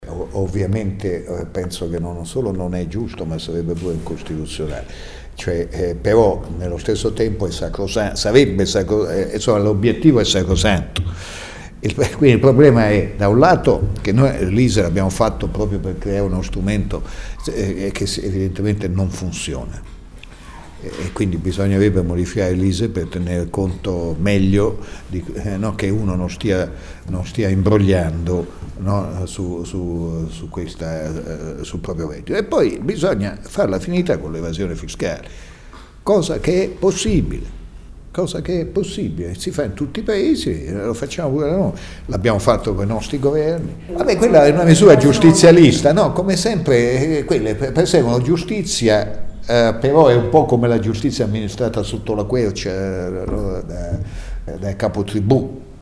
L’ex Ministro ha poi risposto a una domanda circa la proposta della CGIL bolognese di istituire delle differenti soglie di esenzione per le tariffe, basate sulla distinzione tra lavoro autonomo e lavoro dipendente.